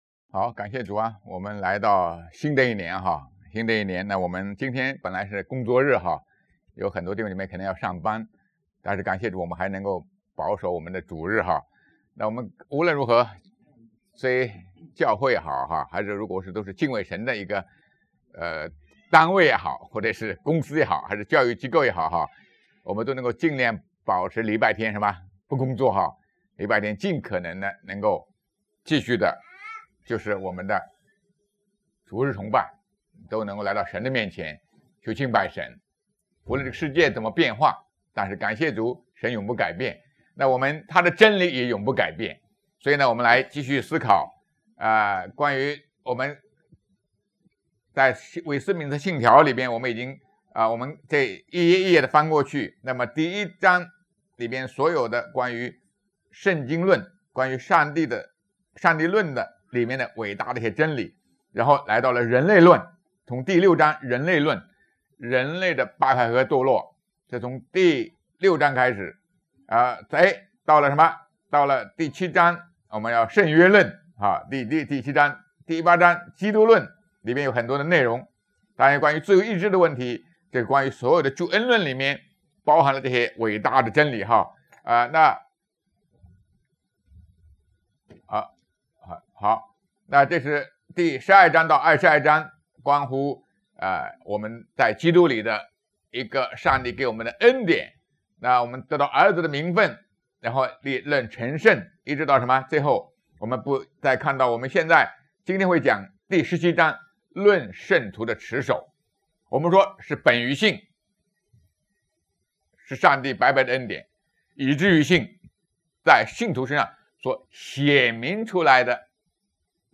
圣经讲道